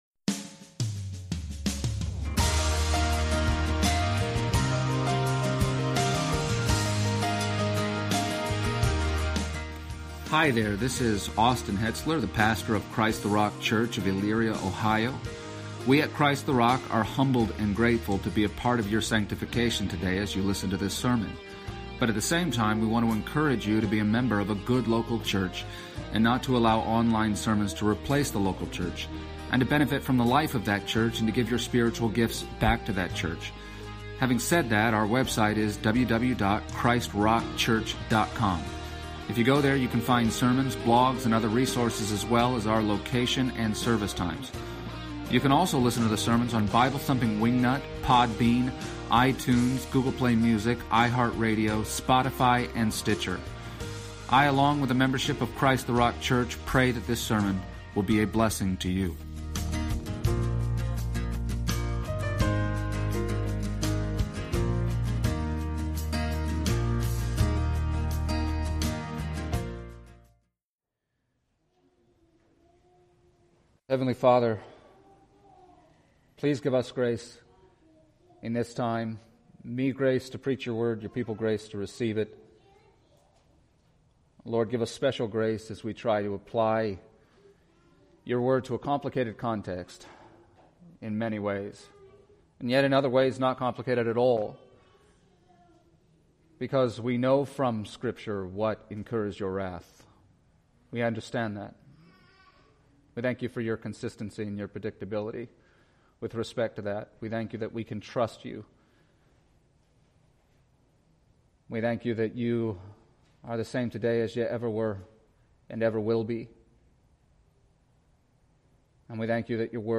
Passage: Romans 1:24-32 Service Type: Sunday Morning